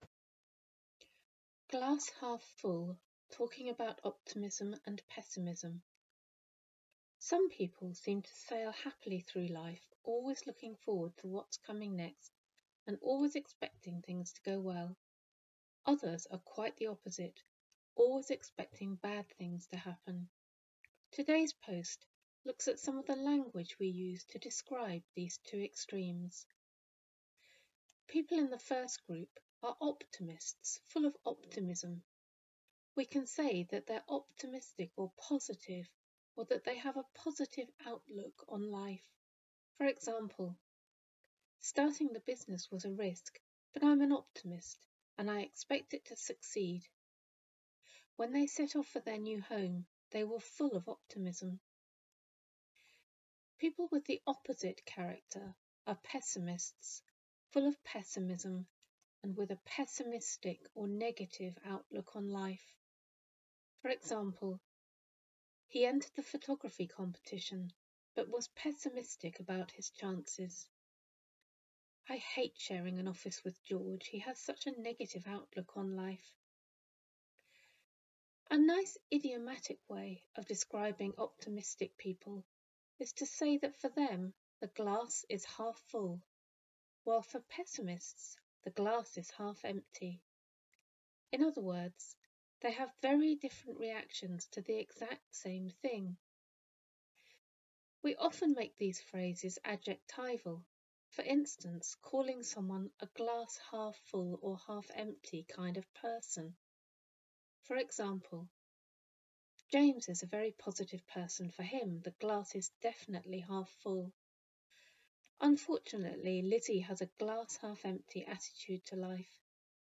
I often listen to your posts, and the volume is always low, even when I have maxed out the volume on my ipad.